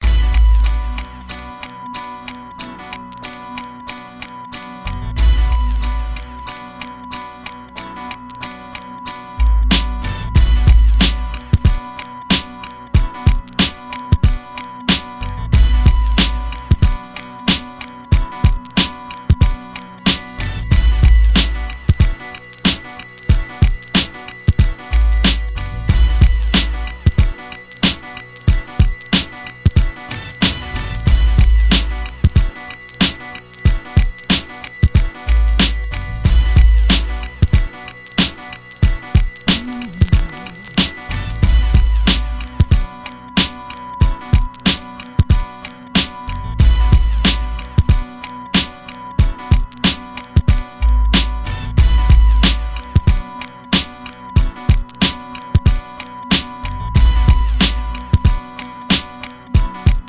Original Instrumental Composition